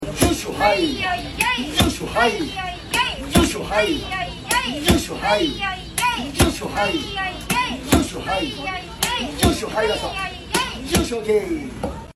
mochimochi pounding!